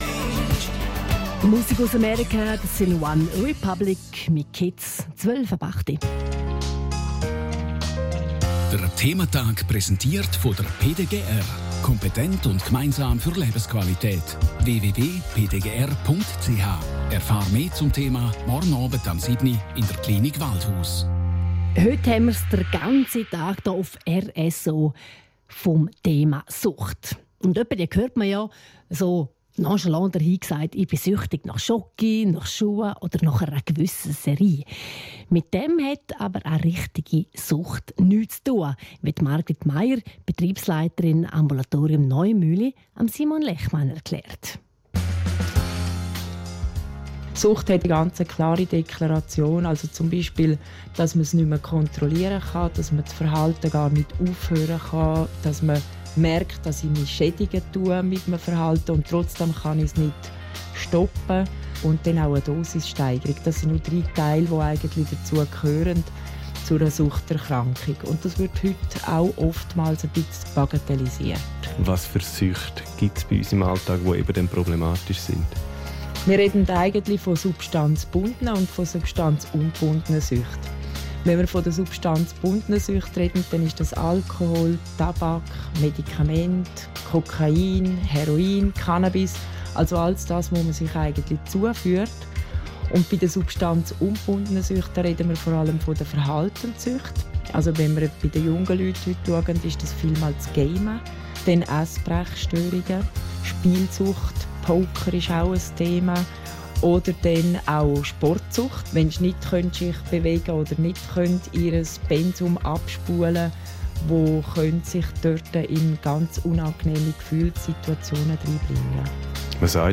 Radiobeiträge